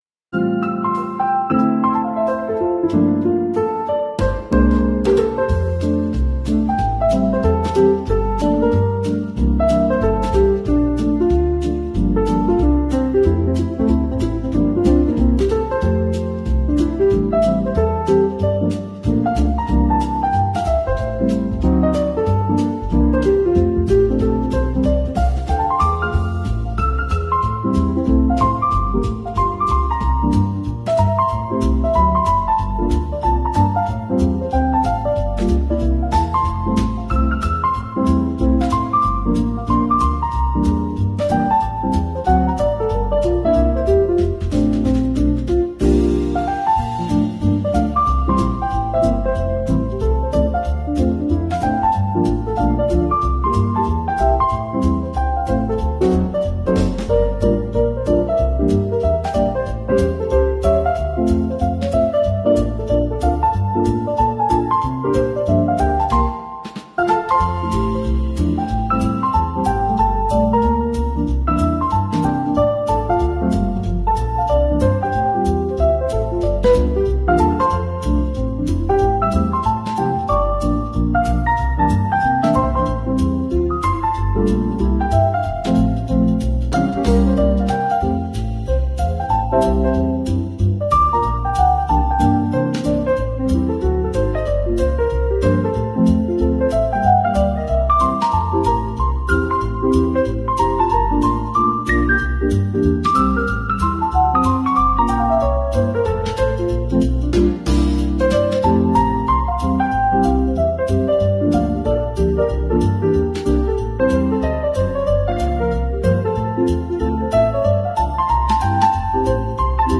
Drift into the mellow groove